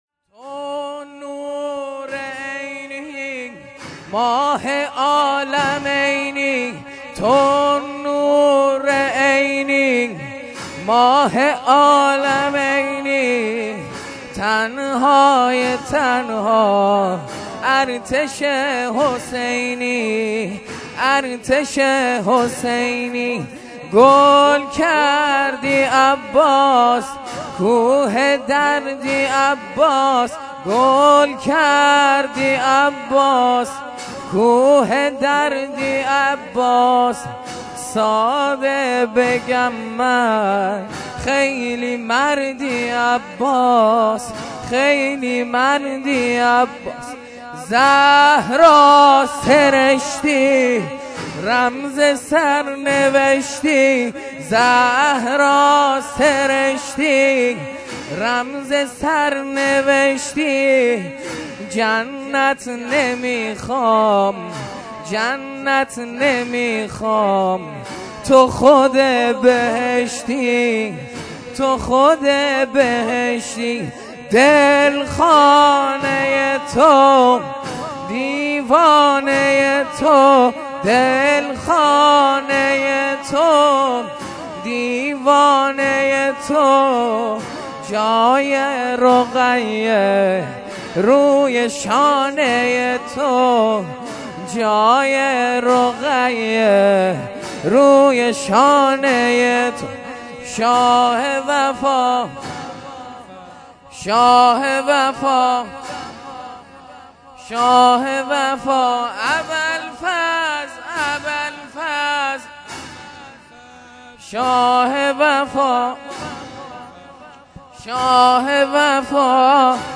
زنجیر زنی هیات محسن انصار المهدی
شب چهارم محرم الحرام ۹۷